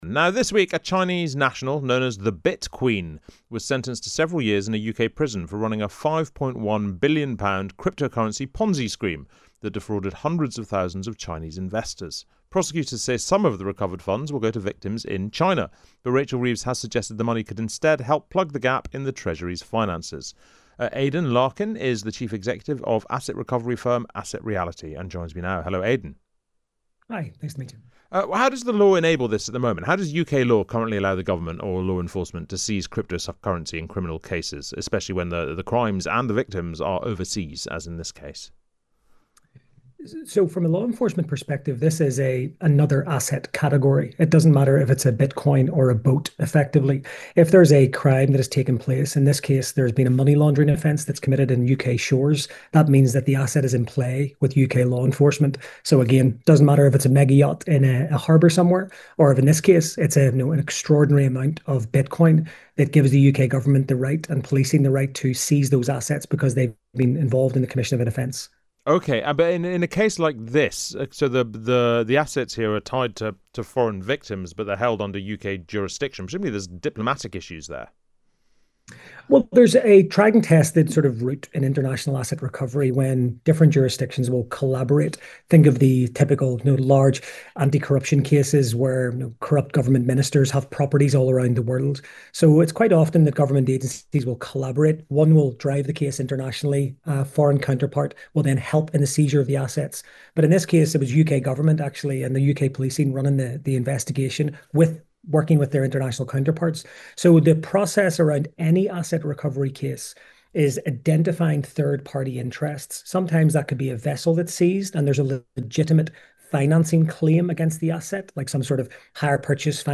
This episode was originally recorded for Times Radio on 13th November 2025.